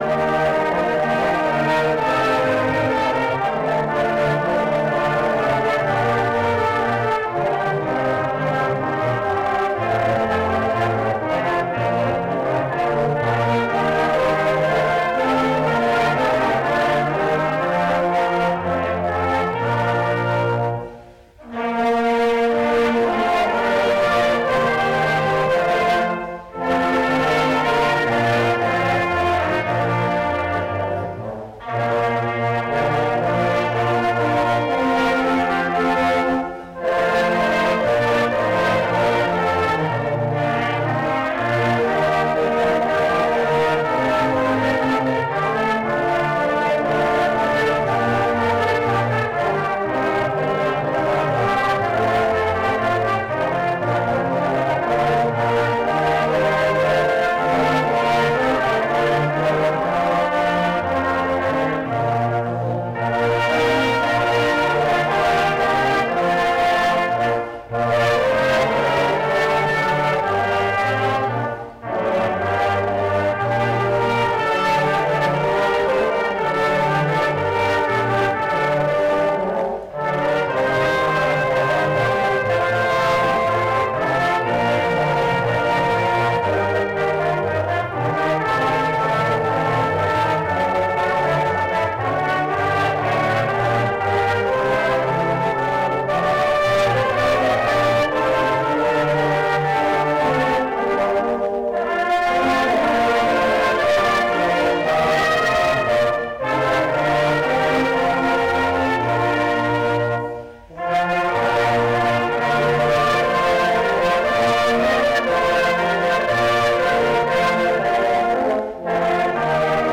Gottesdienst zum Erntedankfest - 05.10.2025 ~ Peter und Paul Gottesdienst-Podcast Podcast